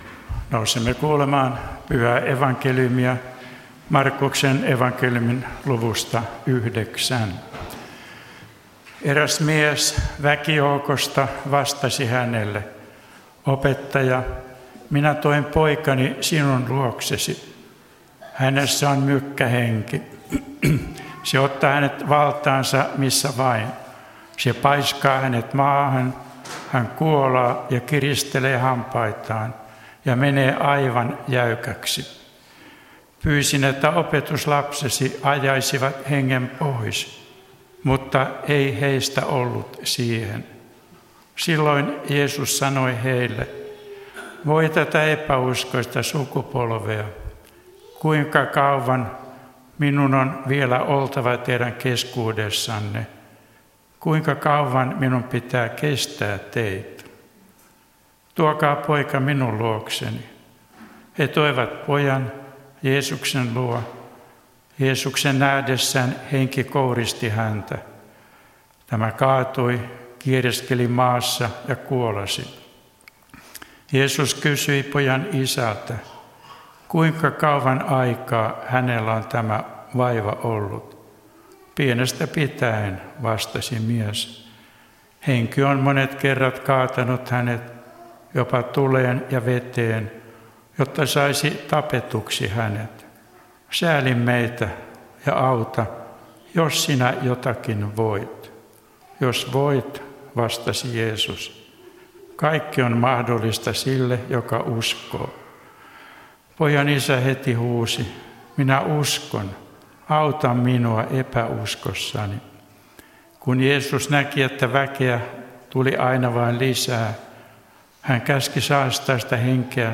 Kokoelmat: Tampereen Luther-talo